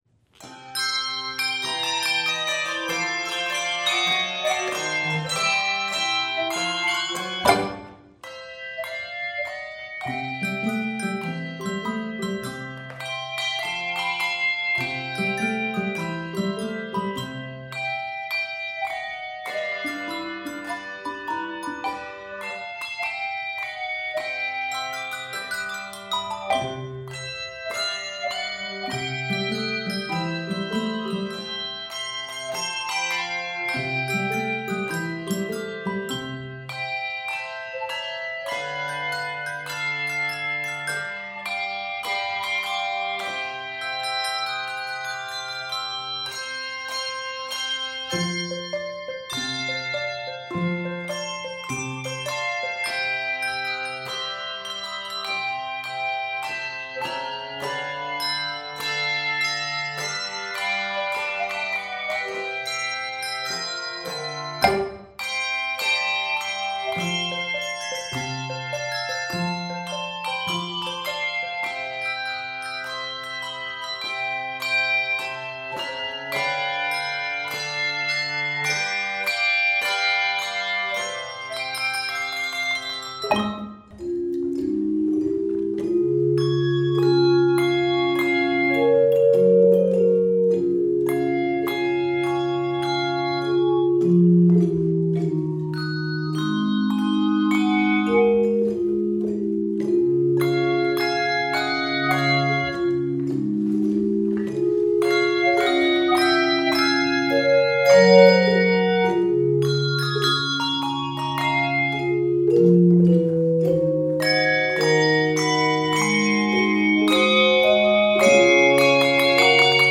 Southern Gospel classic
Keys of C Major and Db Major.